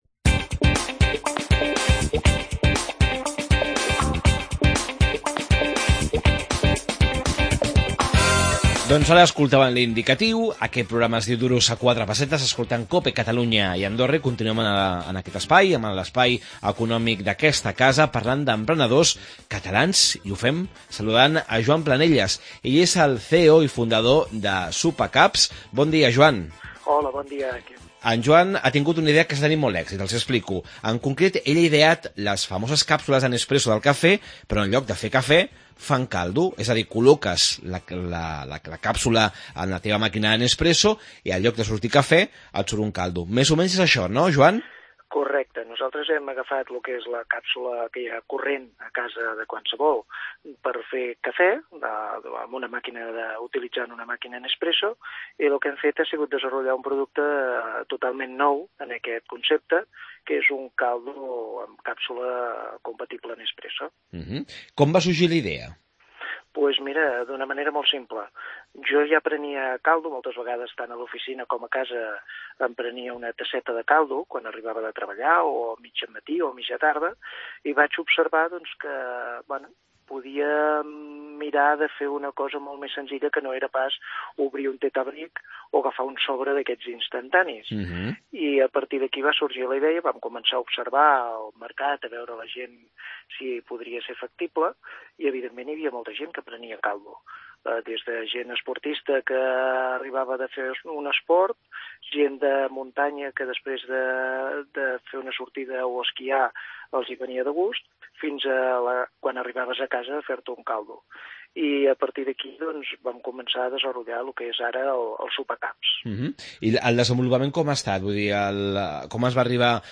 AUDIO: Un emprenedor català ha creat les càpsules de cafè per on surt caldo i no cafè. Entrevista